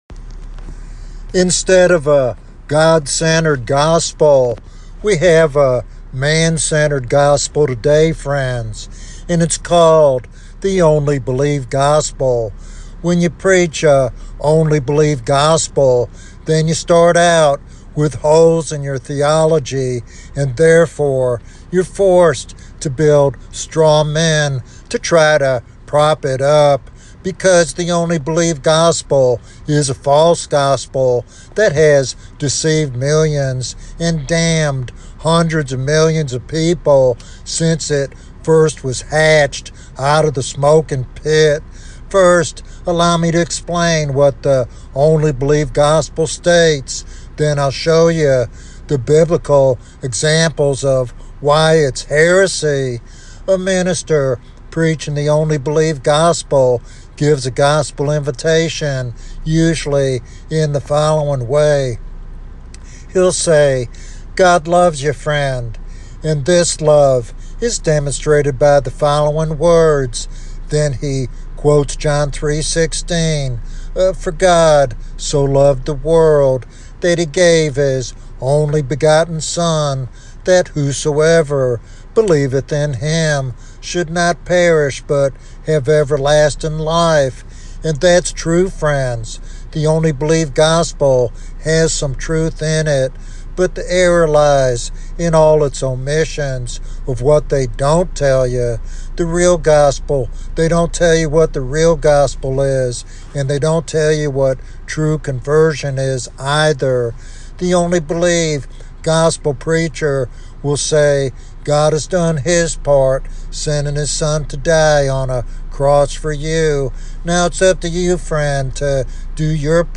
Untitled Sermon
This sermon is a passionate plea for revival and spiritual renewal in the last days.